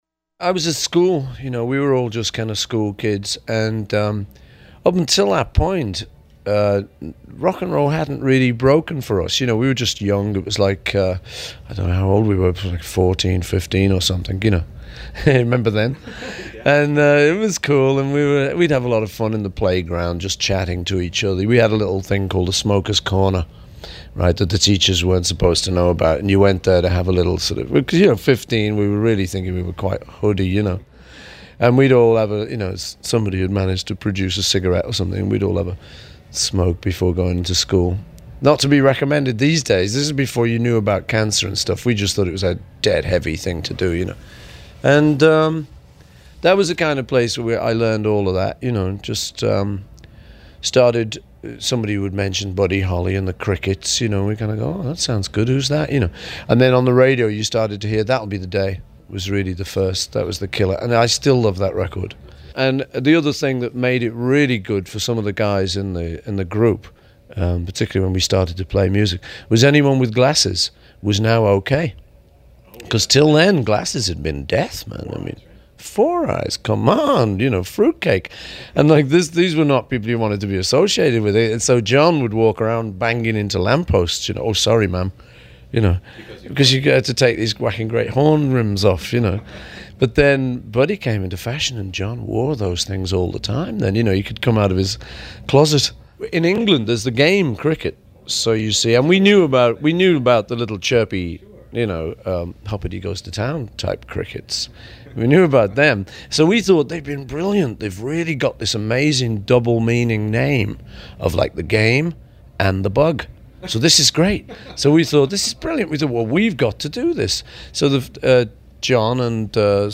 I asked boyhood friend Paul McCartney to remember John Lennon for us.
john-lennon-tribute.mp3